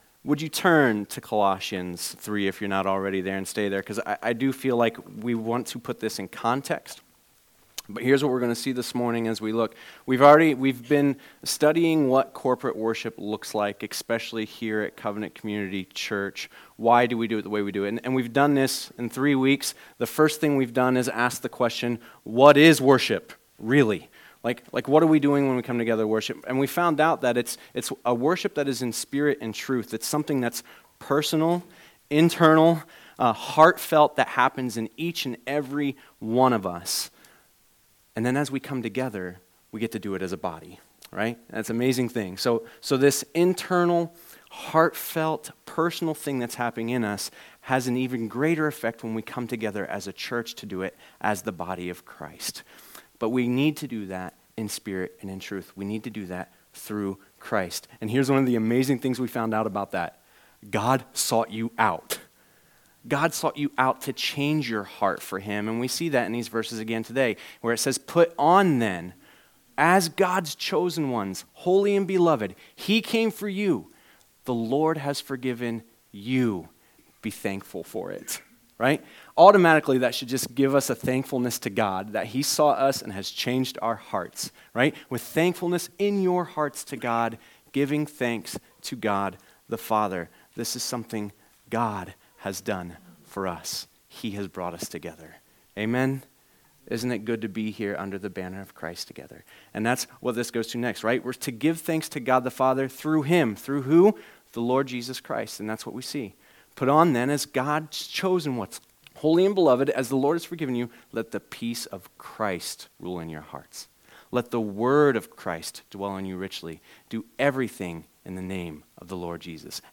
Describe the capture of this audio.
There were some recording issues during the first portion of this message. It picks up a few minutes into the sermon.